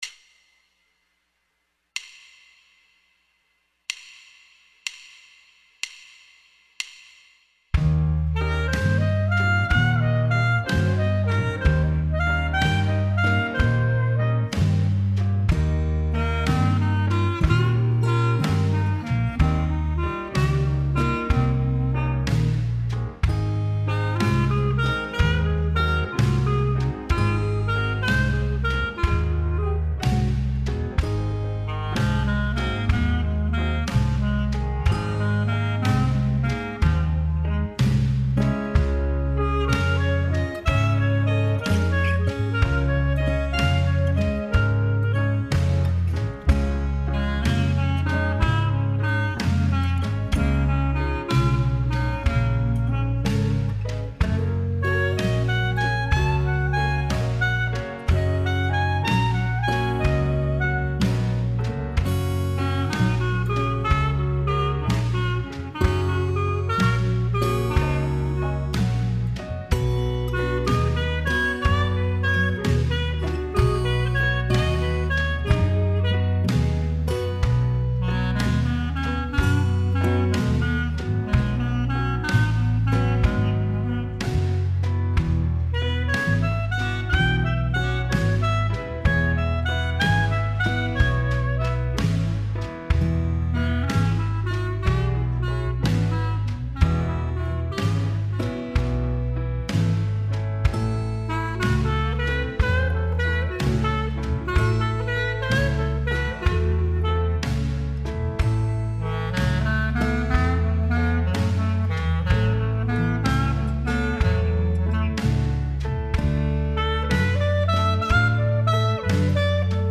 Let’s start with a typical blues riff.
Here it is in all 12 keys at 62 beats per minute.